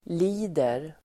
Uttal: [l'i:der]